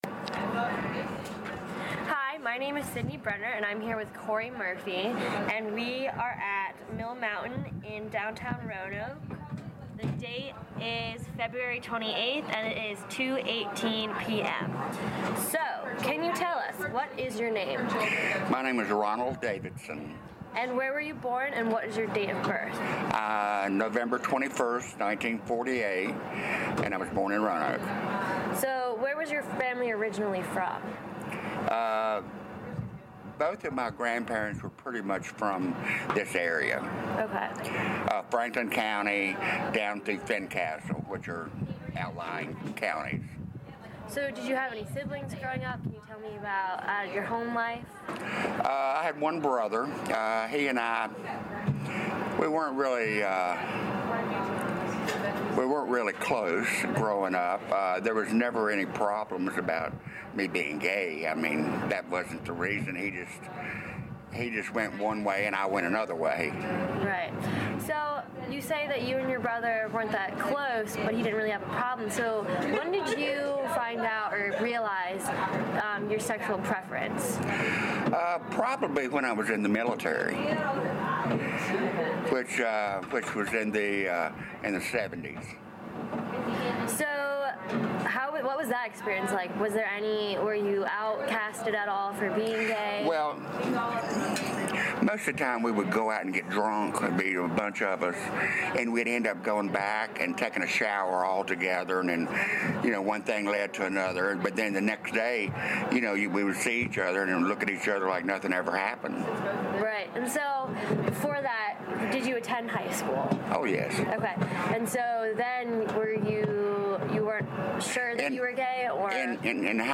Oral History Interview
Location: Mill Mountain Coffeehouse